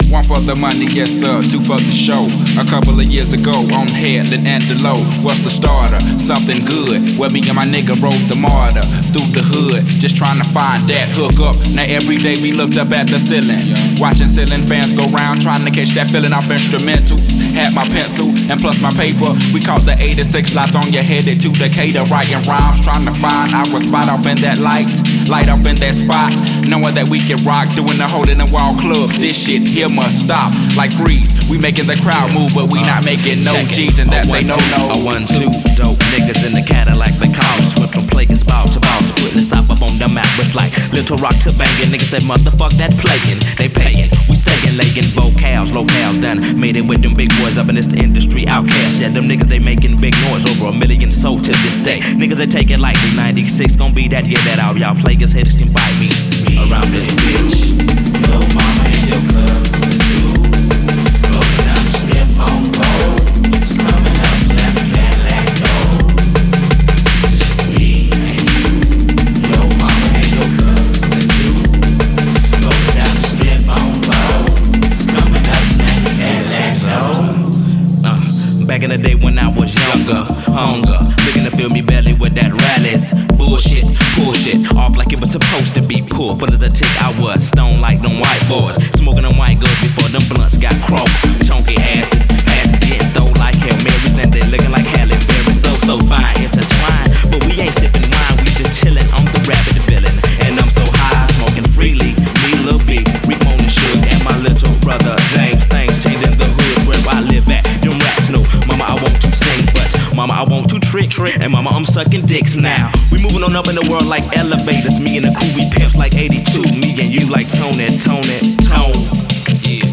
This is a even better mashed up than expected.